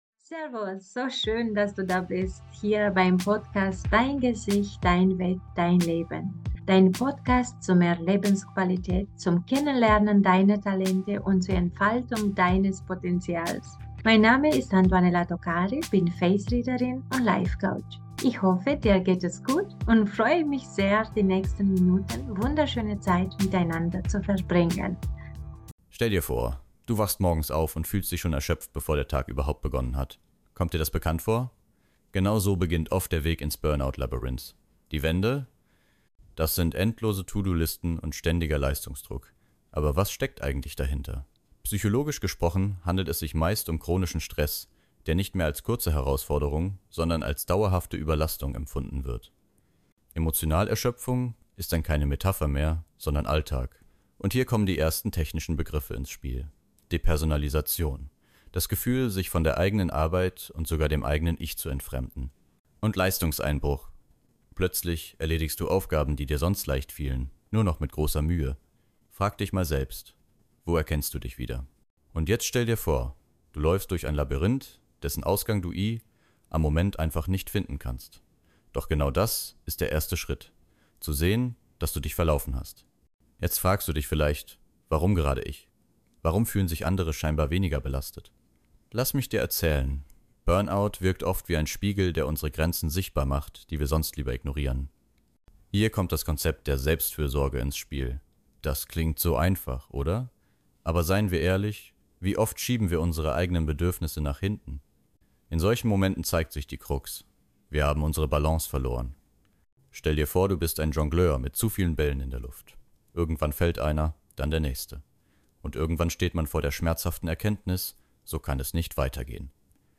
Hinweis: Dieses Interview wurde mit Unterstützung von KI
BurnoutKI_A02.mp3